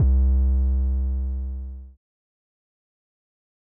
808 (GONE, GONE).wav